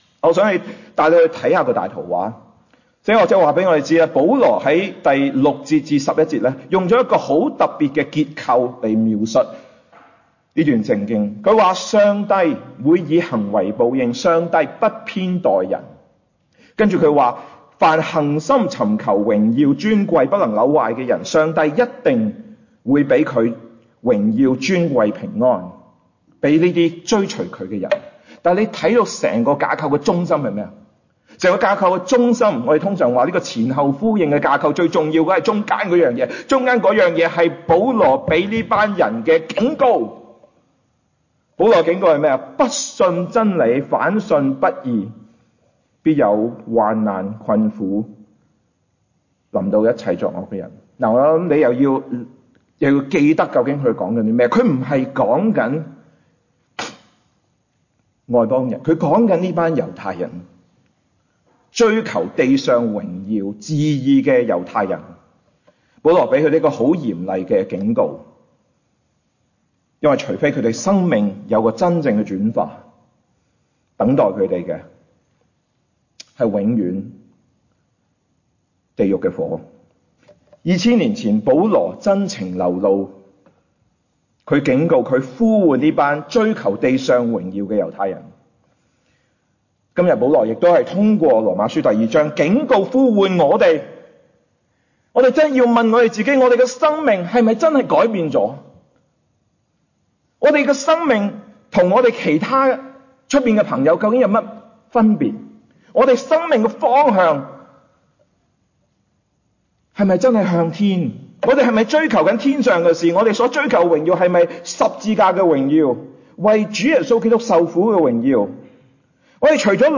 分類：靈修讀經